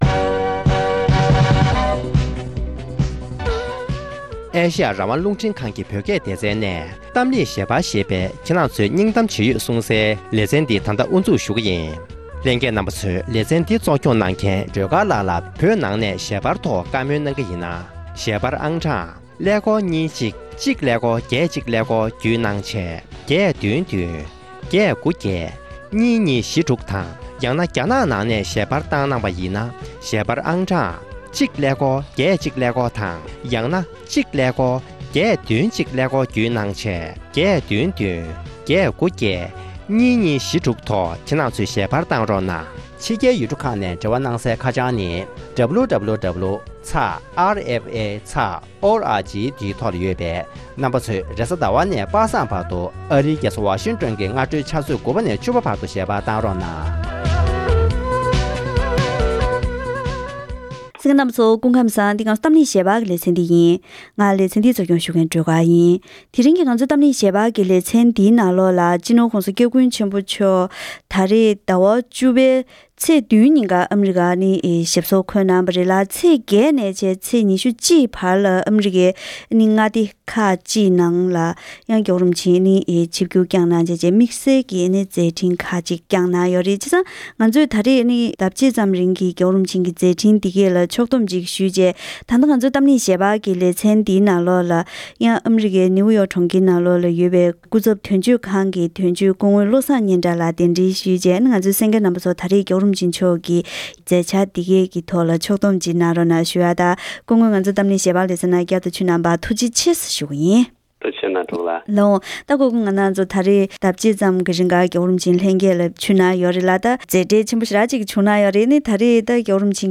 ༄༅༎དེ་རིང་གི་གཏམ་གླེང་ཞལ་པར་ཞེས་པའི་ལེ་ཚན་ནང་དུ། སྤྱི་ནོར་༧གོང་ས་༸སྐྱབས་མགོན་ཆེན་པོ་མཆོག་གིས། ཨ་རིའི་ནང་ཟླ་ཕྱེད་ཙམ་གྱི་མཛད་འཕྲིན་བསྐྱངས་གནང་བའི་སྐོར། བཙན་བྱོལ་བོད་མིའི་སྒྲིག་འཛུགས་ཀྱི་བྱང་ཨ་རི་སྐུ་ཚབ་དོན་གཅོད་དང་ལྷན་དུ་བཀའ་མོལ་ཞུས་པར་གསན་རོགས་ཞུ།